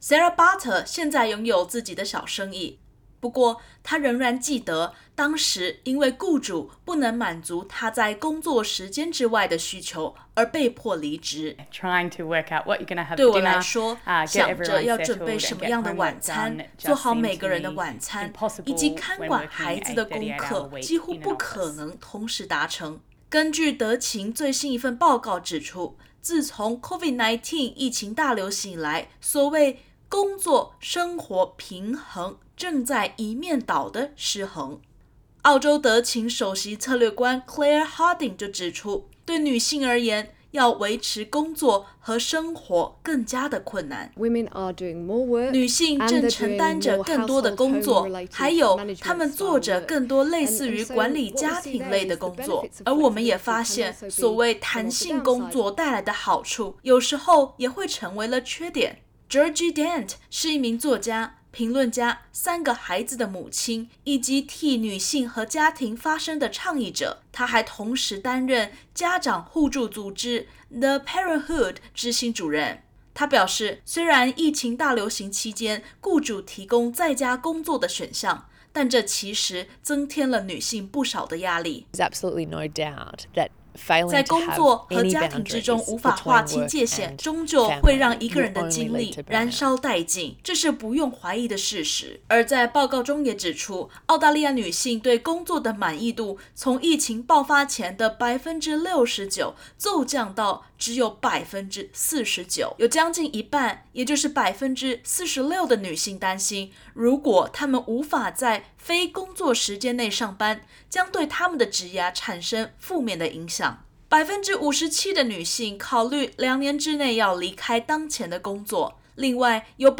德勤（Deloitte）发布的最新报告指出，自从COVID-19疫情全球大流行之始，女性在职场中，就承担着相比男性更多的工作量。点击首图收听采访音频。